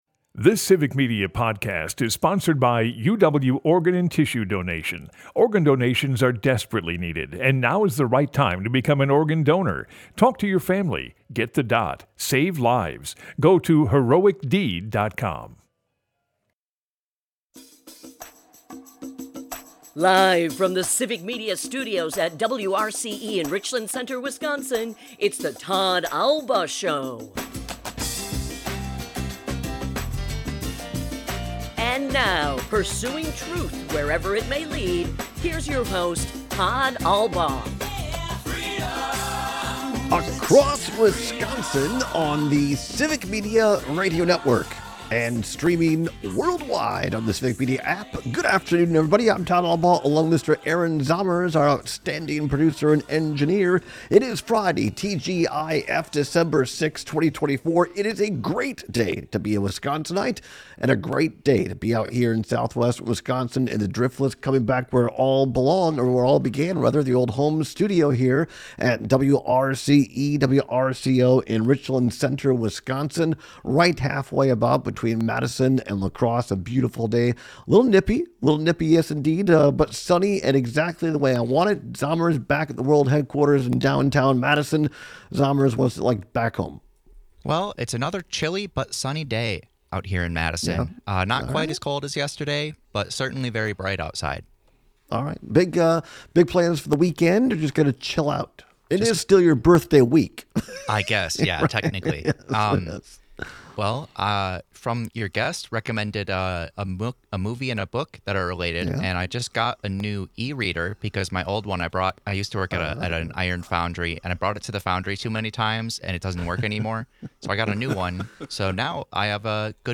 Right off the bat, he is joined by his old boss, former Republican State Senate Majority Leader Dale Schultz.